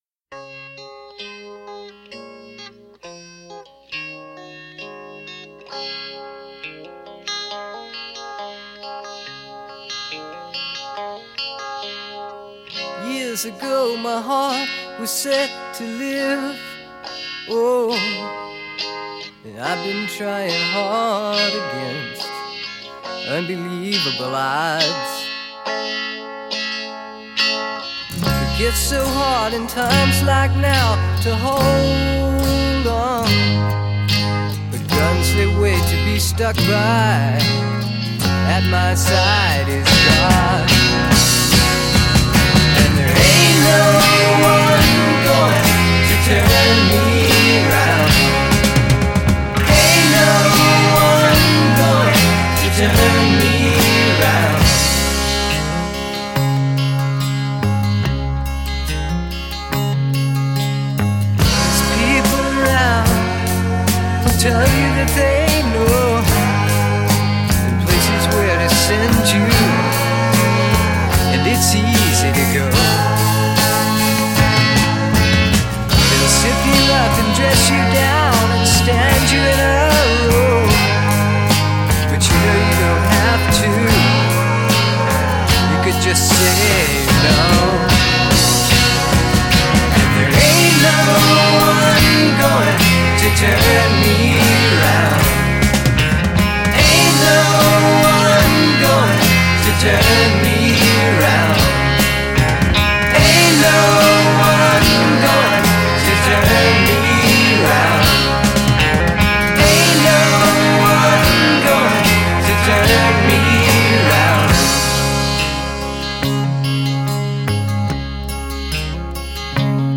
simple, light pop with sweet harmonies and jangly guitars.